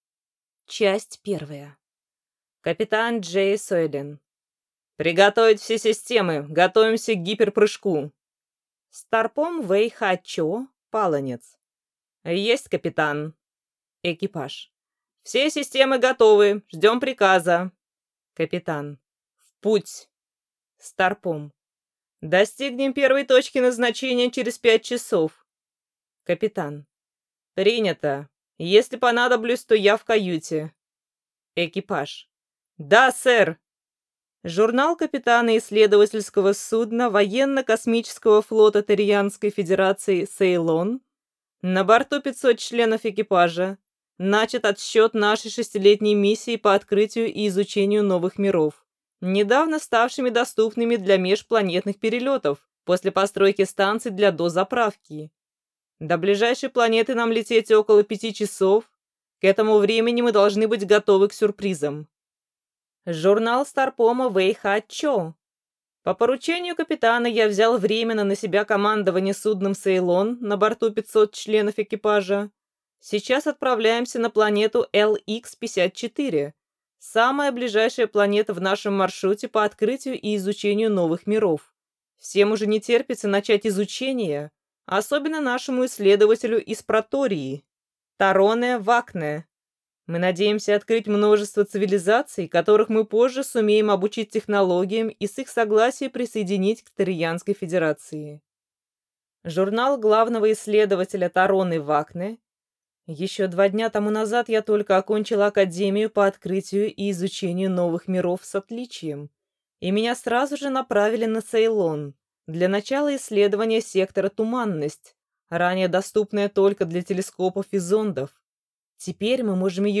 Аудиокнига Сэйлон | Библиотека аудиокниг